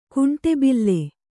♪ kuṇṭe bille